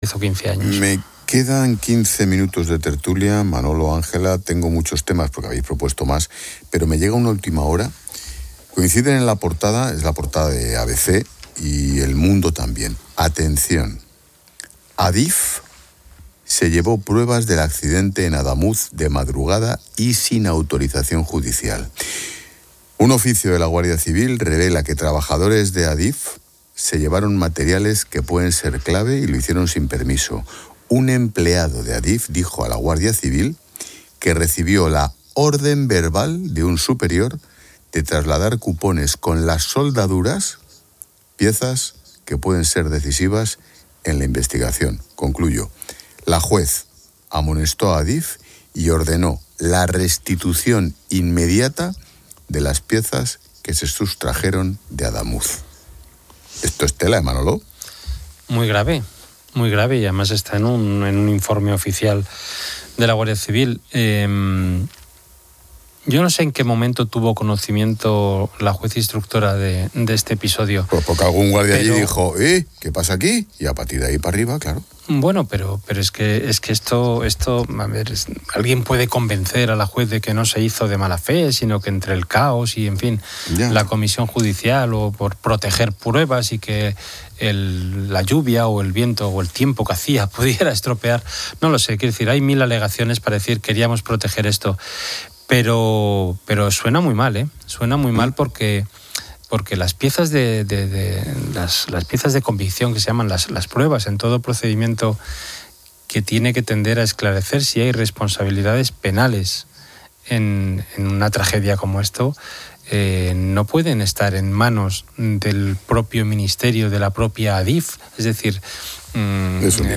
un asunto que ha sido analizado en el programa 'La Linterna' de COPE por el director del programa